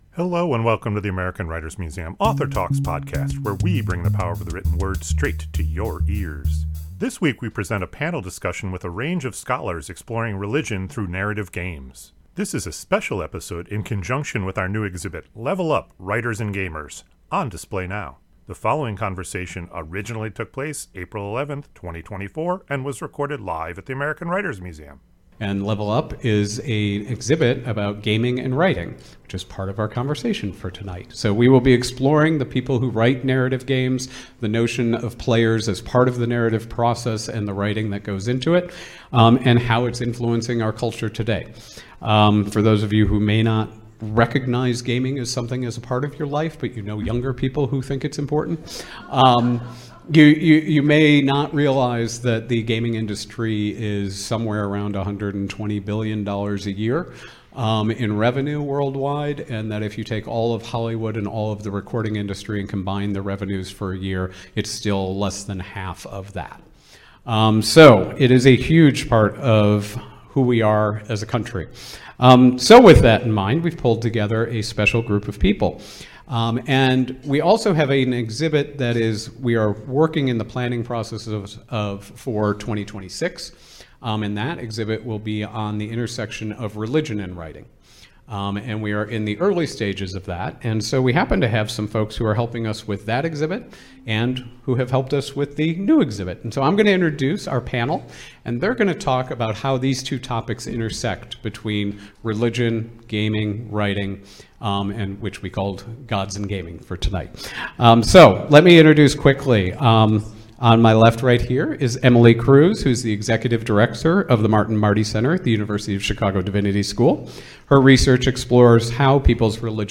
This week, we present a panel discussion with a range of scholars exploring religion through narrative games.